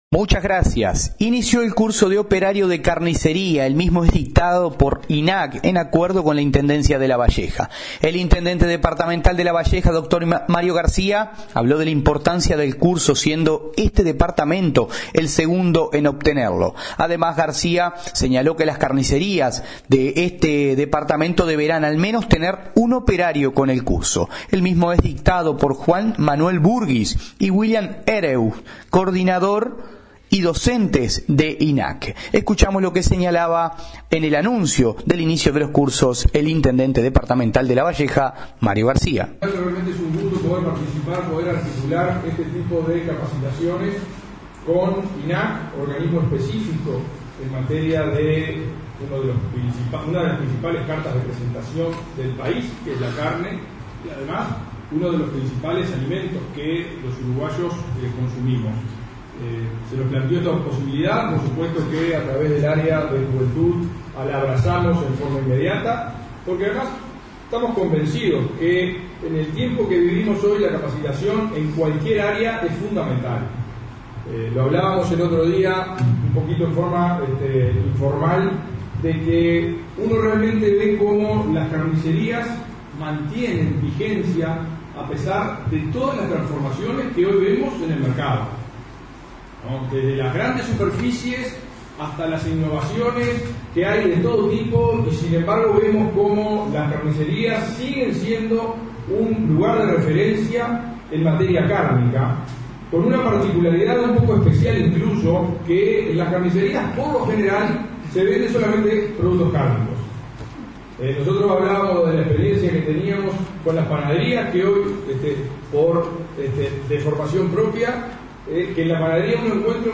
El intendente de Lavalleja, Mario Garcia, se refirió en conferencia de prensa a la importancia de este curso, siendo este departamento el segundo en obtenerlo.